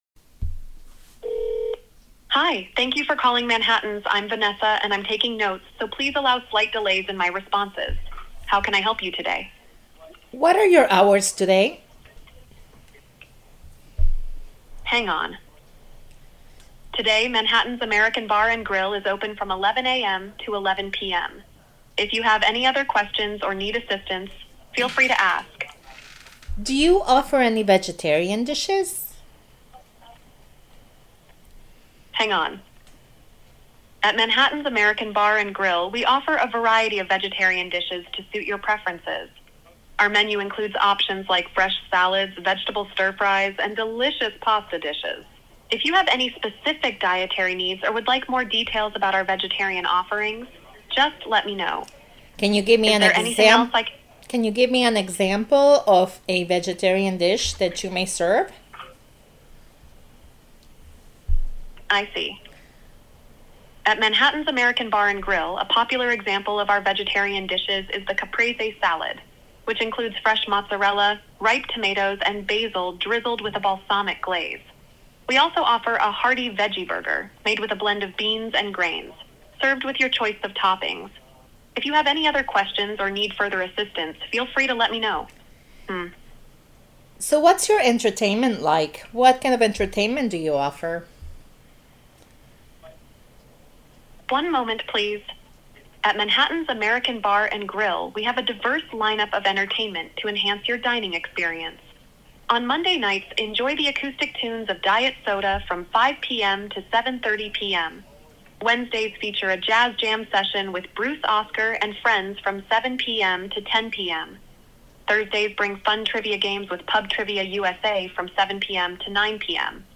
Conversation with the Hostess
Vanessa, AI Host
Conversation-with-Host.mp3